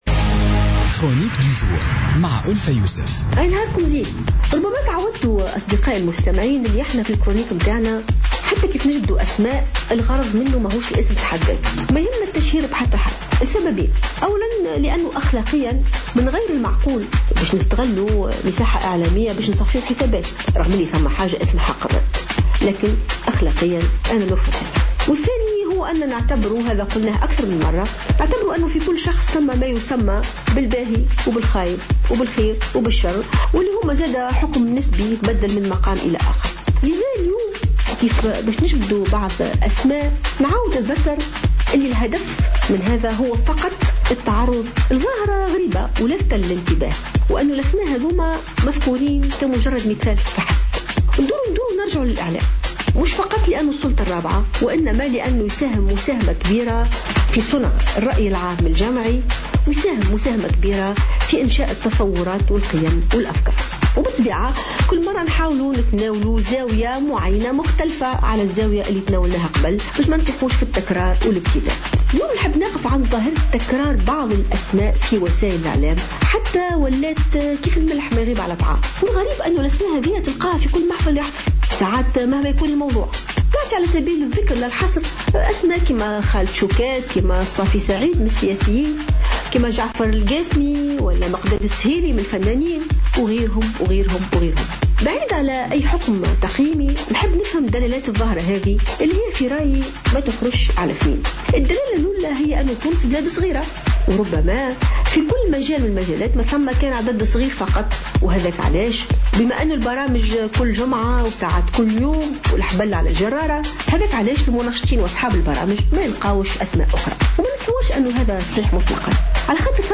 علّقت الجامعية والمفكرة ألفة يوسف في افتتاحية اليوم الأربعاء على تكرّر الظهور الإعلامي لبعض الوجوه الفنية والسياسية في مختلف القنوات التلفزية في تونس.